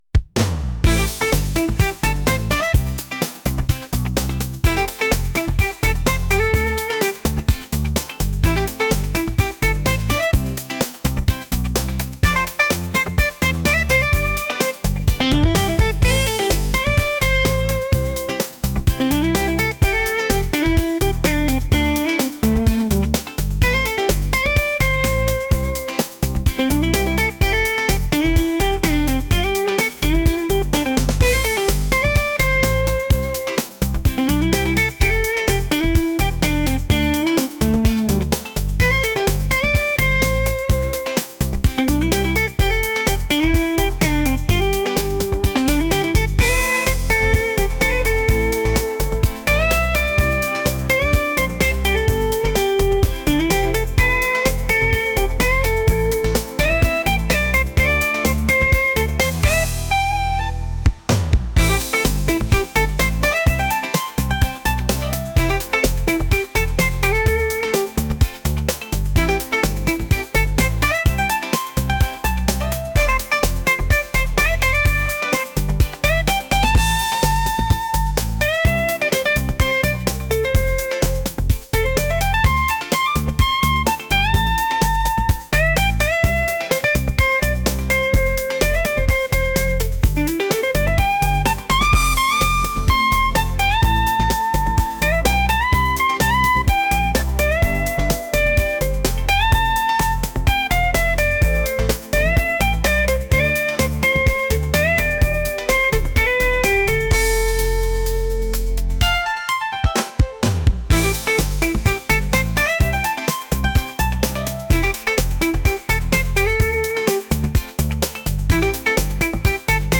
electronic | rock | pop | funk